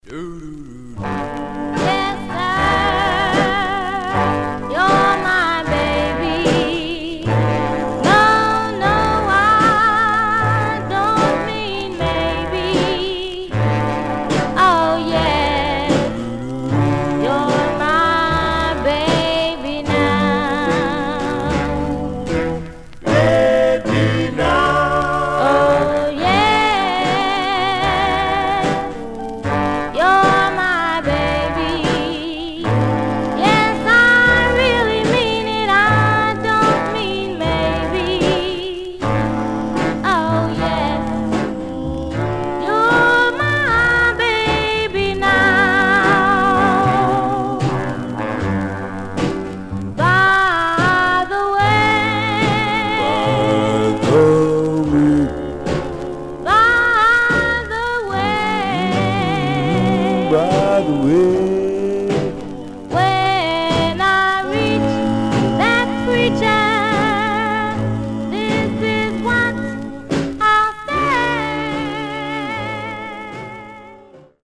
Mono
Male Black Groups